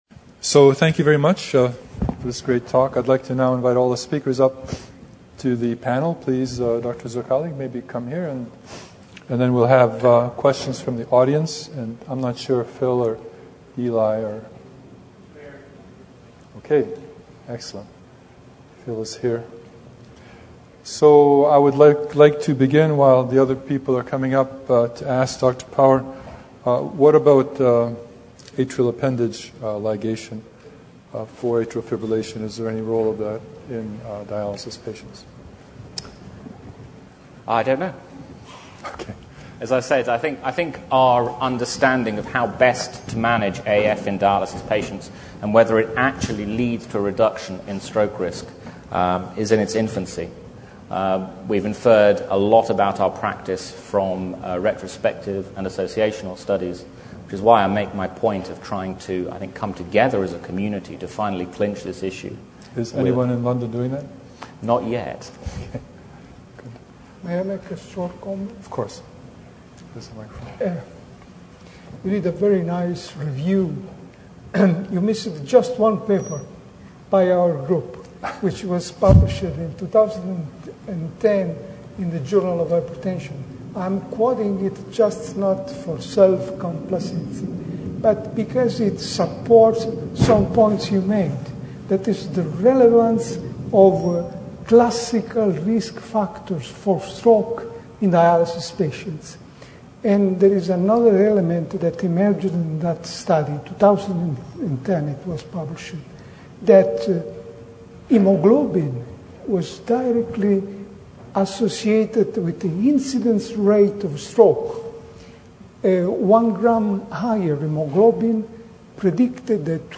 MD Discussion Drs.